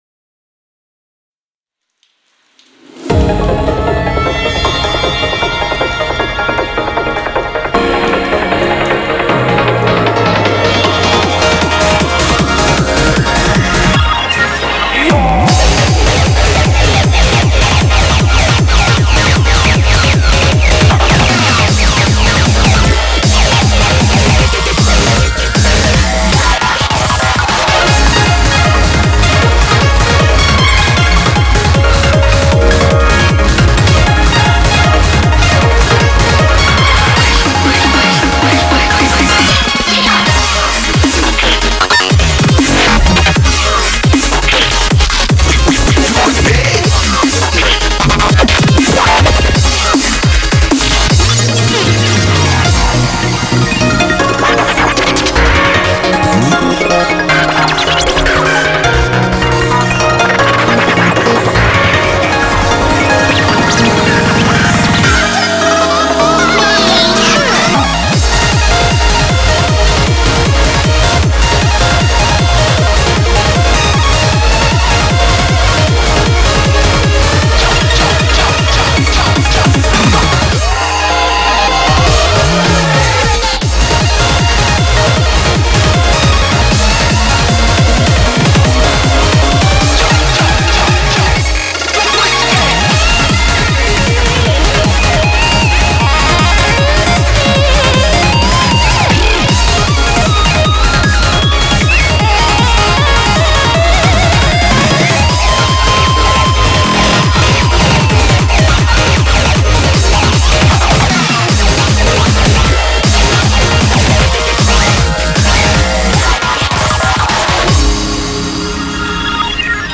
BPM78-310
Audio QualityPerfect (High Quality)
TIP: Main BPM is 155.